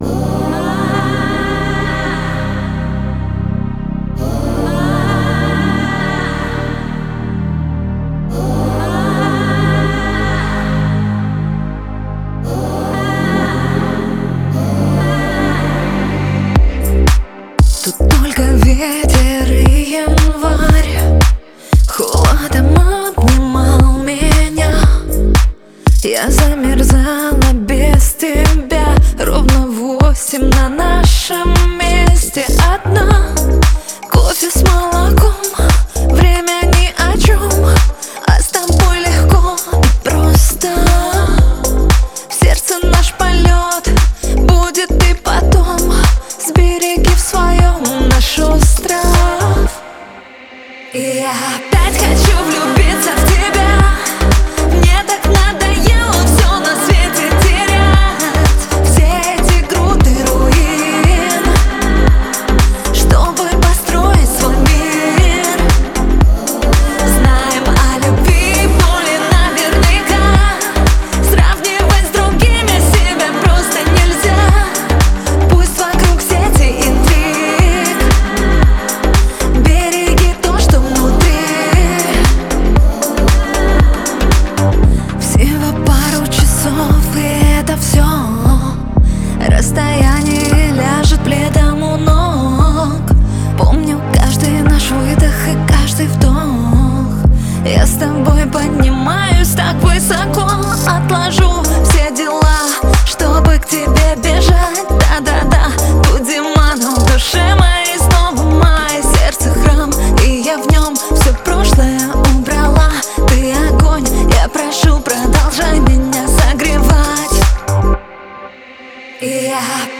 это яркий пример поп-музыки с романтическим настроением.